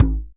DJEM.HIT01.wav